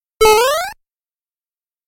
Categoria Messaggio